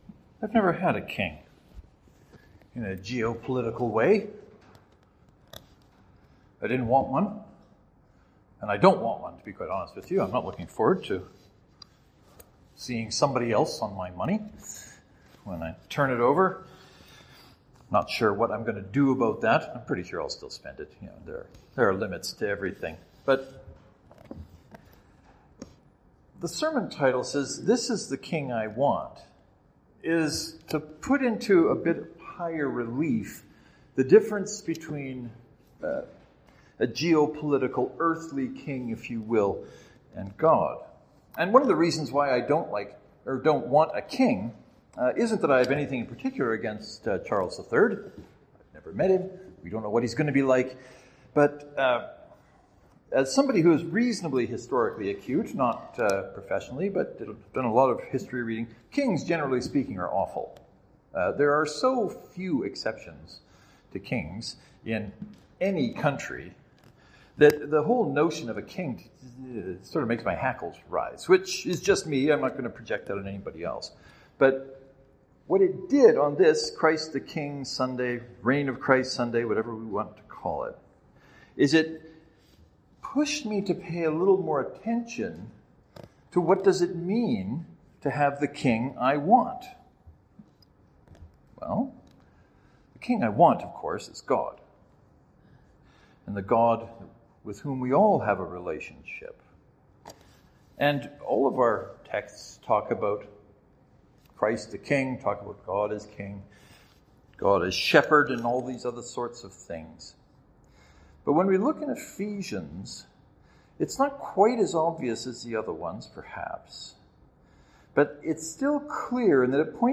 This dilemma will make more sense when you listen to the sermon called, “This is the king I want.” Other than making a remark or two about geo-political kings the bulk of the sermon is me revisiting the last time I preached on Ephesians 1:15-23.
“This is the king I want” St. Mark’s Presbyterian (to download, right-click and select “Save Link As .